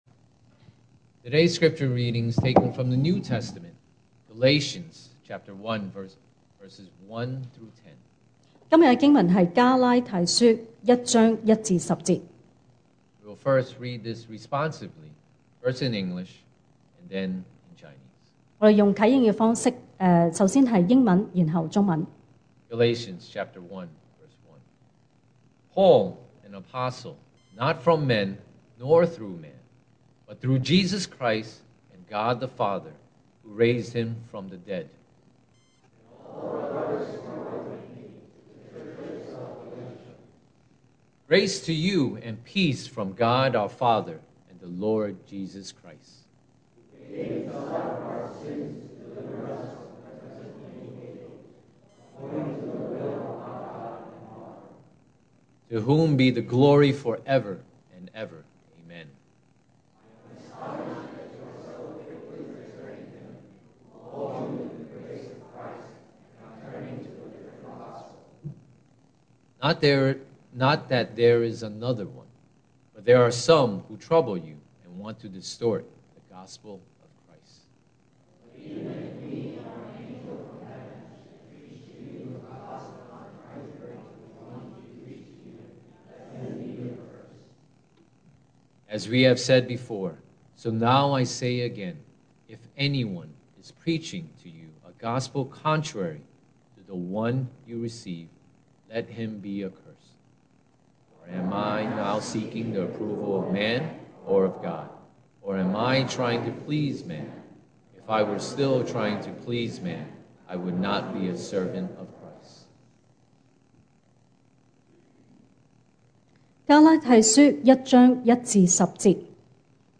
2023 sermon audios 2023年講道重溫 Passage: Galatians 1:1-10 Service Type: Sunday Morning